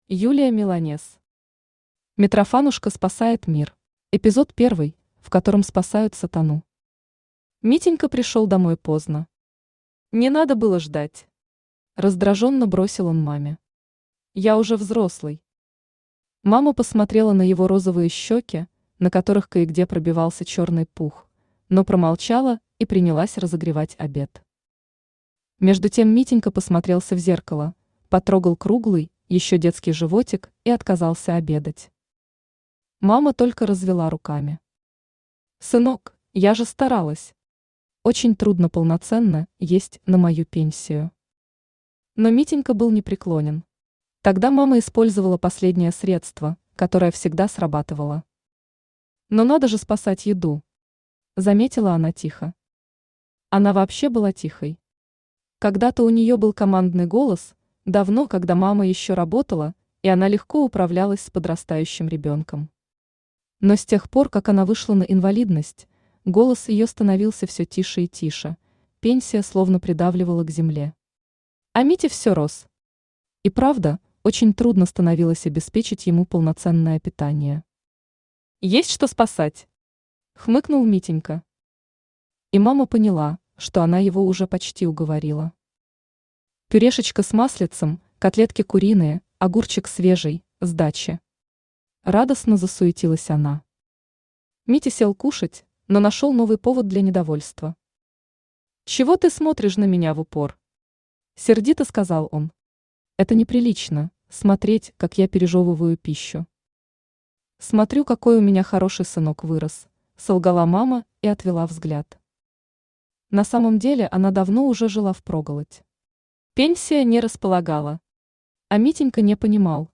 Aудиокнига Митрофанушка спасает мир Автор Юлия Миланес Читает аудиокнигу Авточтец ЛитРес.